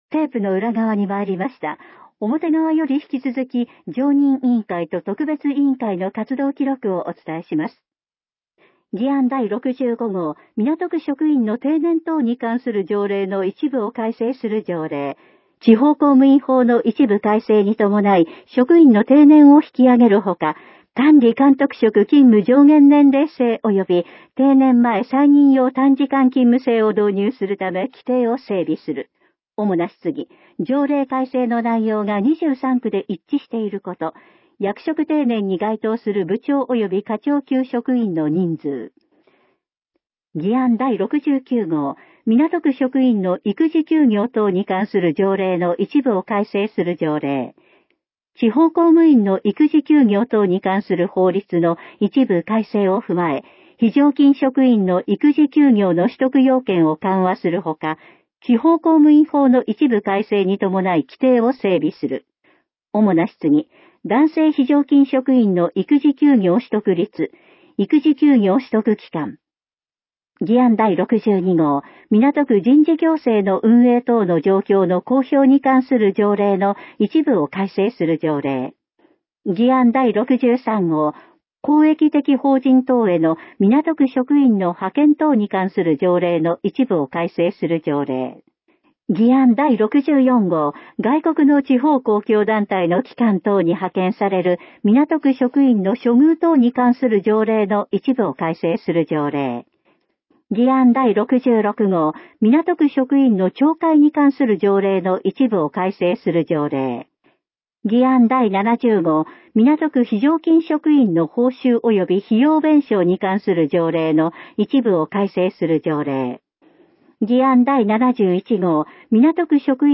掲載している音声ファイルは、カセットテープで提供している音声ファイルをそのまま掲載しています。そのため、音声の冒頭で「テープの裏側にまいりました」のような説明が入っています。